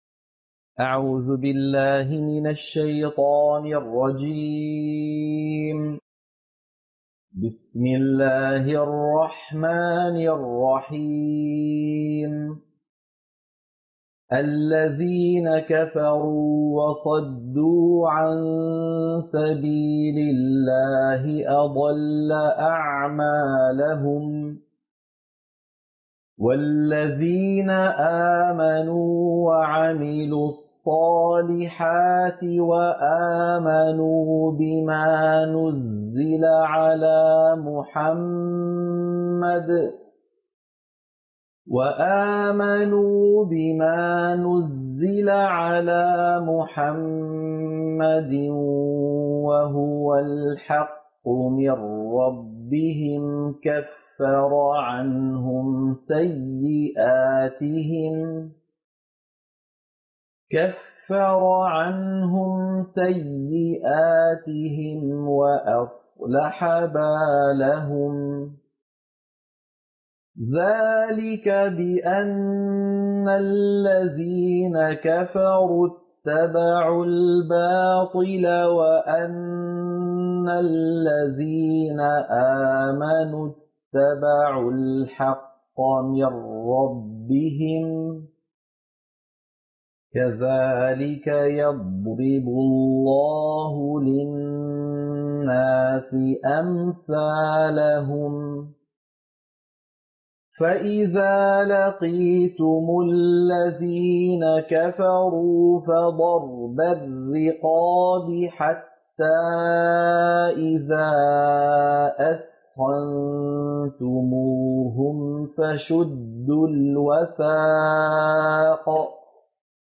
سورة محمد - القراءة المنهجية